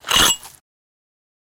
Ice Scrape | Sneak On The Lot